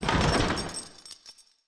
SFX_Motorcycle_PickUp_01.wav